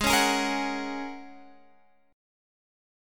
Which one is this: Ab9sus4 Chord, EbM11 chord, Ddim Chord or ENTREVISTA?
Ab9sus4 Chord